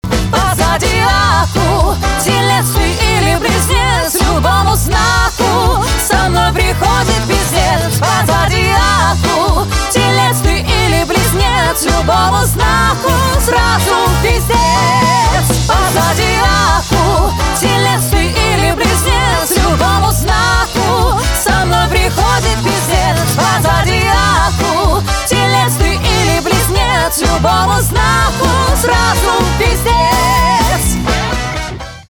шансон
пианино , барабаны , труба
без слов